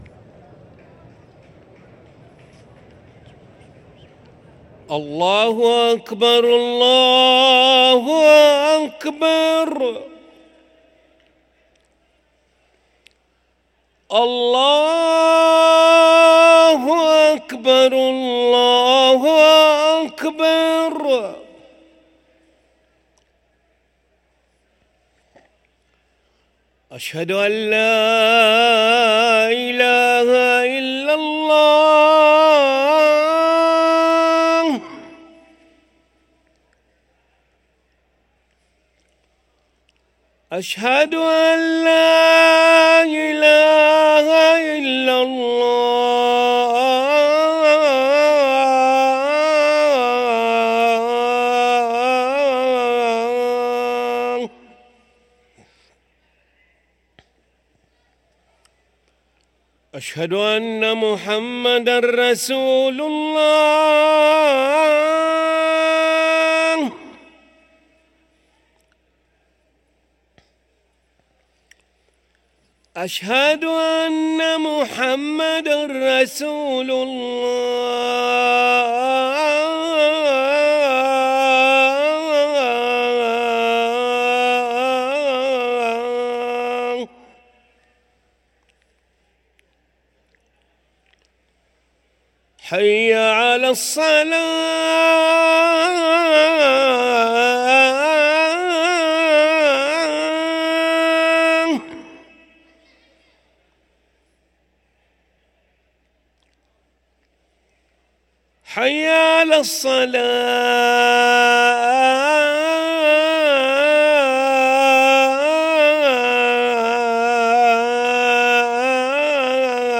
أذان العشاء للمؤذن علي ملا الخميس 20 ربيع الأول 1445هـ > ١٤٤٥ 🕋 > ركن الأذان 🕋 > المزيد - تلاوات الحرمين